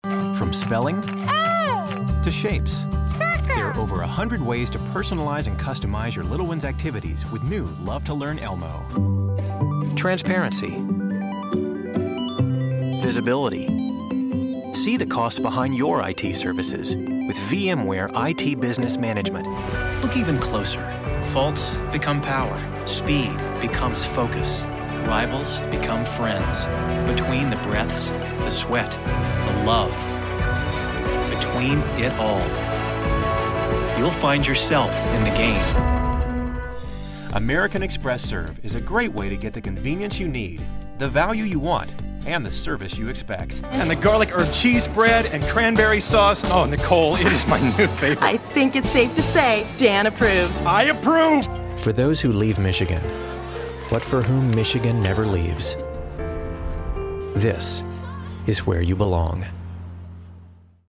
Adult
standard us
commercial